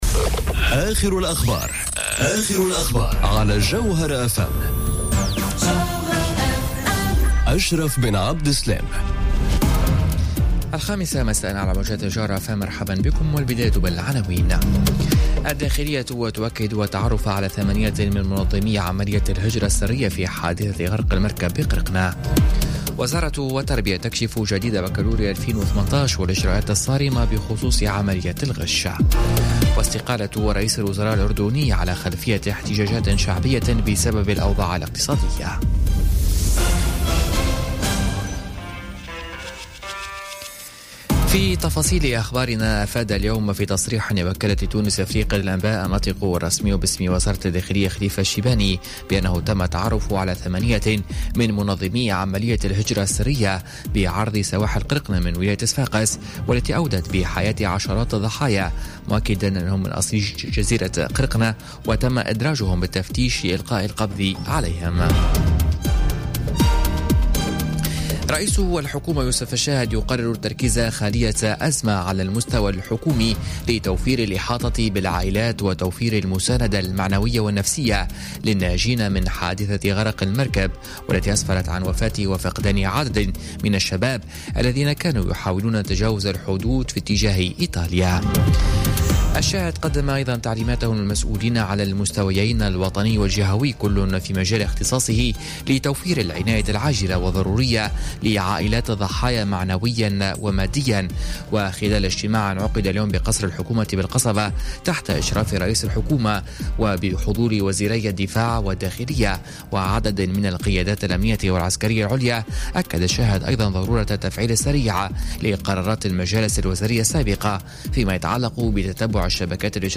نشرة أخبار الخامسة مساء ليوم الاثنين 4 جوان 2018